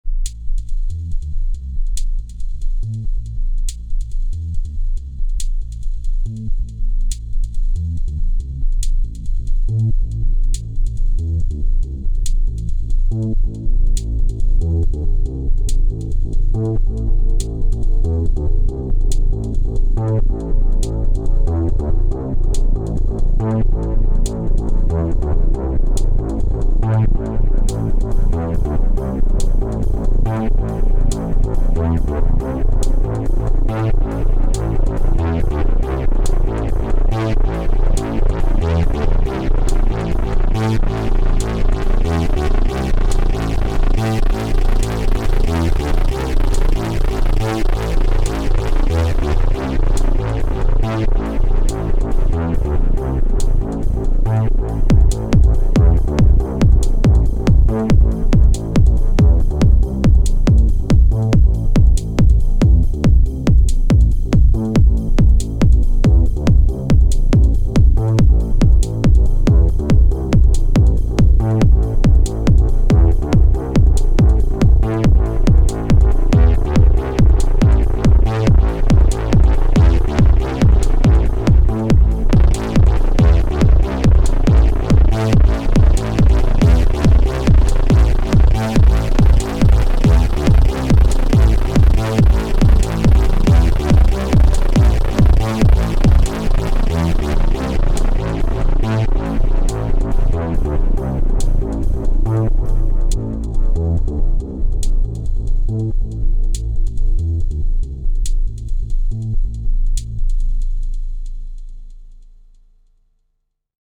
:play Oberheim Matrix-6R Split Sawth **:
** FX by Logic, Drums by E-mu Esi2000
oberheim_matrix-6r_-_sounds_demo_-_split_sawth_external_fx.mp3